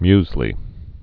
(myzlē)